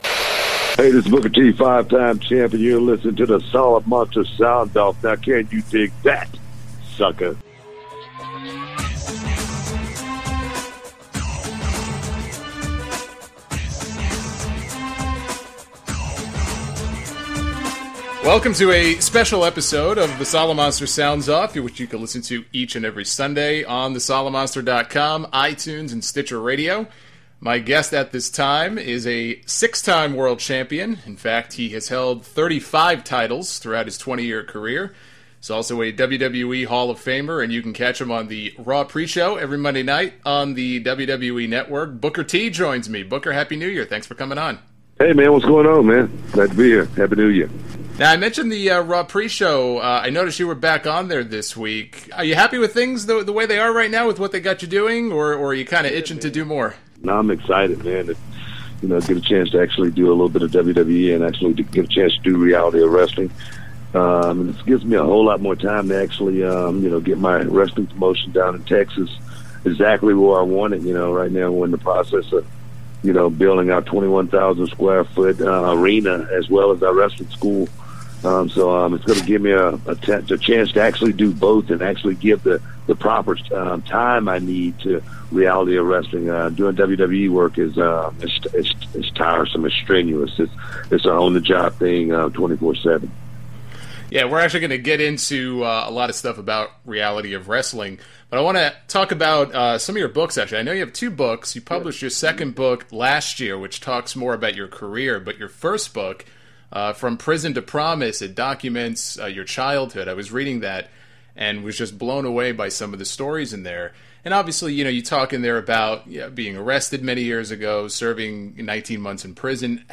Sound Off 417 - INTERVIEW WITH WWE HALL OF FAMER BOOKER T!
SOUND OFF 417 is a special episode featuring an interview with five-time WCW champion and WWE Hall of Famer BOOKER T!